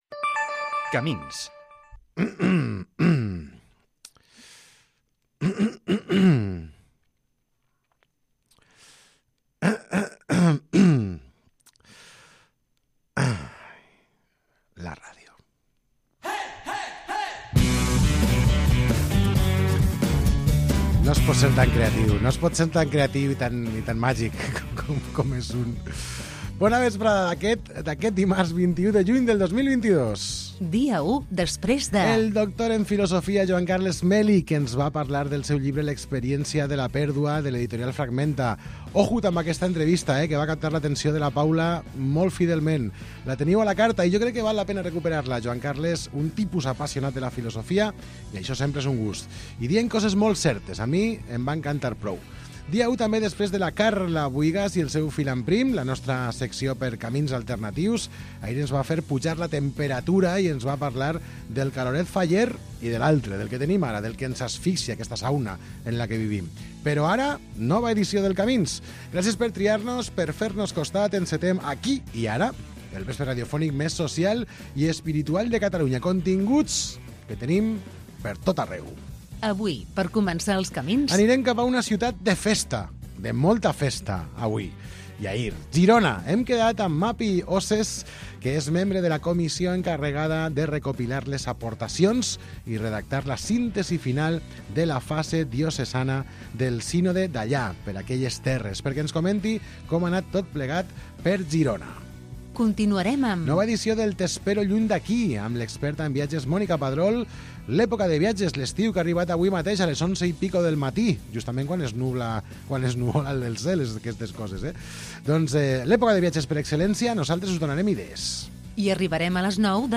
Magazin del vespre que ofereix una àmplia varietat de temes que van des de l'actualitat del dia, entrevistes, seccions culturals, socials i esports, passant per l'anàlisi i l'humor. Tot amb un to natural i desenfadat, per acabar el dia amb bones sensacions.